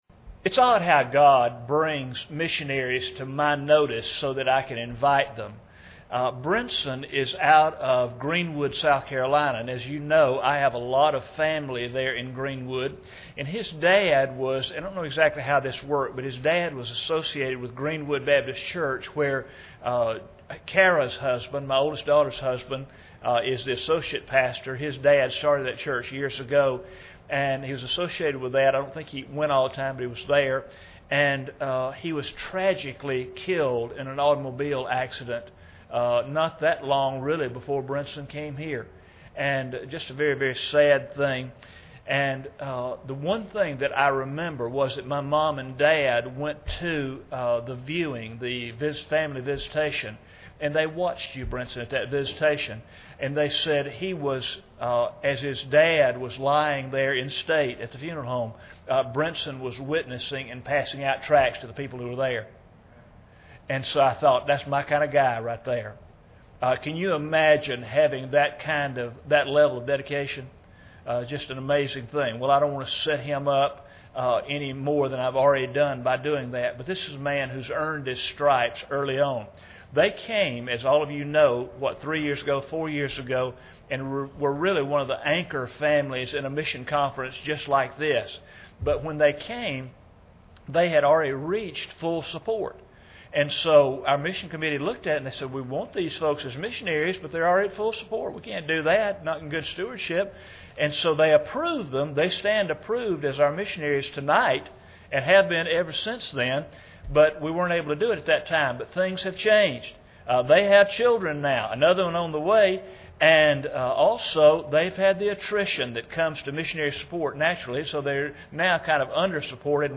Report on the first term of ministry in Niger and the current situation/plans for future ministry. Includes audio recording of presentation video as well as a Q&A period.
Service Type: Sunday Evening